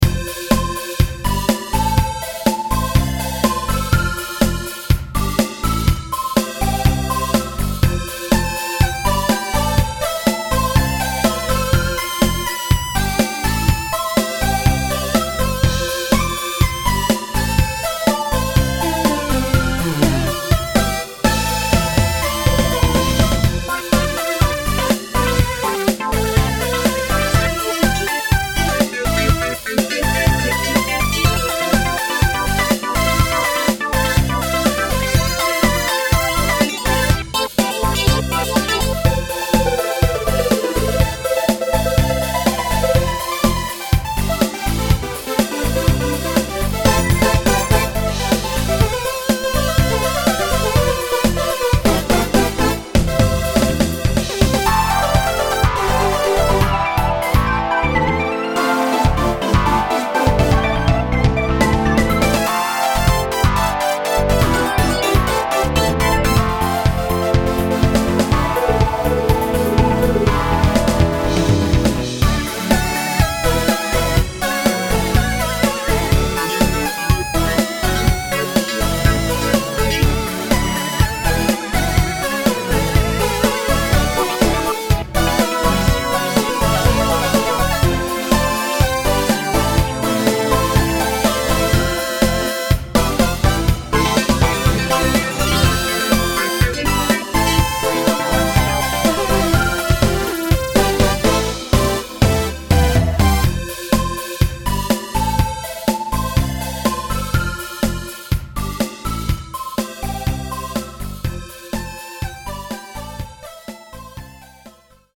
タグ: けだるい,日常
日常の気怠さと適当さ